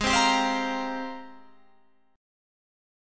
AbM13 chord